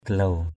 /ɡ͡ɣa-hlau/ 1. (d.) cây trầm = bois d’Aigle. gahluw asar gh*~| asR trầm hương. gahluw asar hapak jang mbuw (tng.) gh*~| asR hpK j/ O~| trầm hương thì ở đâu...
gahluw.mp3